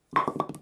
bowlingPinFall_3.wav